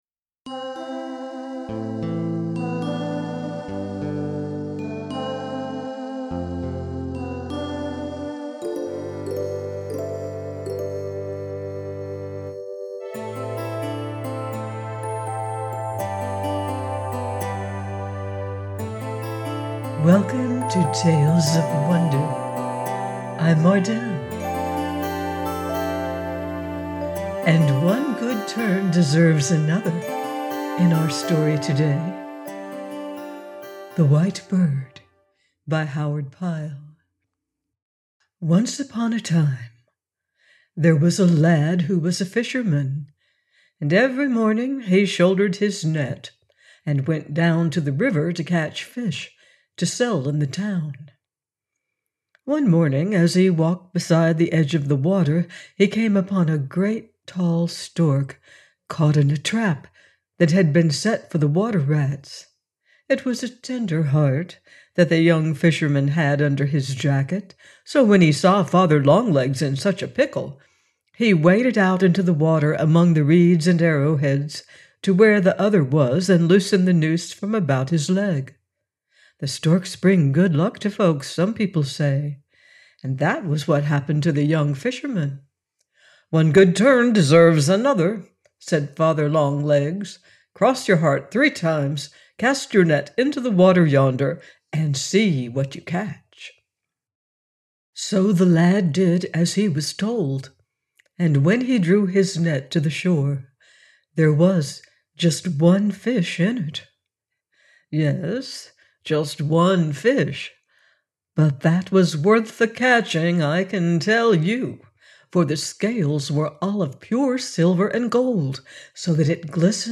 THE WHITE BIRD – by Howard Pyle - audiobook